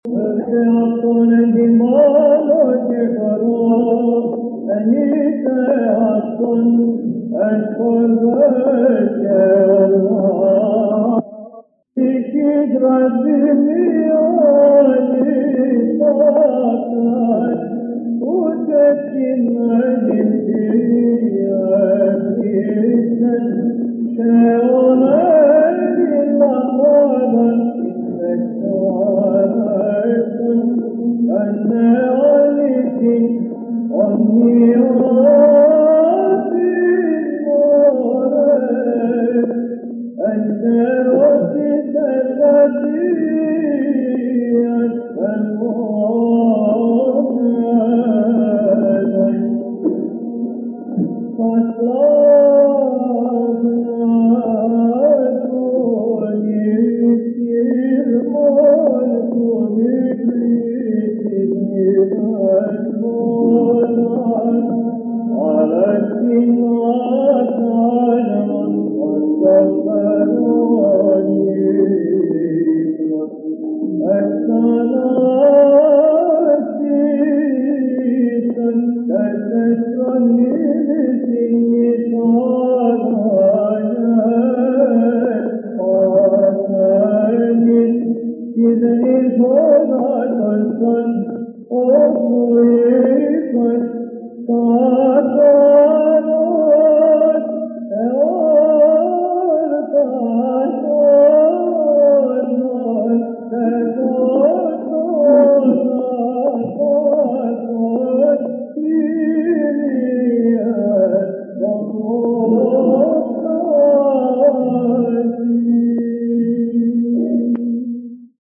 Ὁ τῇ ψυχῆς ραθυμίᾳ, Στανίτσας — ἔτος 1959, (ἠχογρ. Μεγ. Δευτέρα ἑσπέρας)